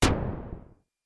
Oberheim Xpander Sink » Oberheim Xpander Sink F4 (Sink6680
标签： midivelocity60 F4 midinote66 OberheimXpander synthesizer singlenote multisample
声道立体声